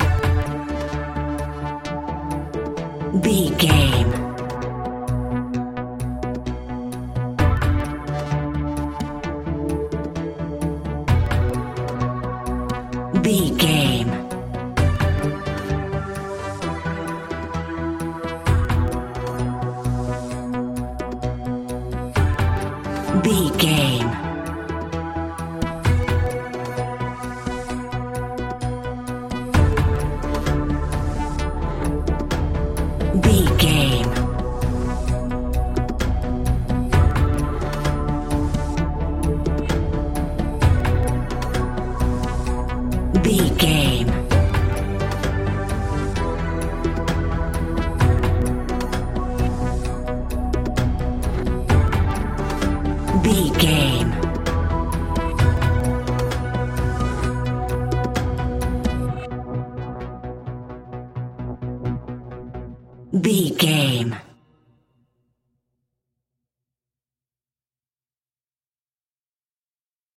In-crescendo
Thriller
Aeolian/Minor
scary
ominous
dark
haunting
eerie
synthesiser
drums
instrumentals
horror music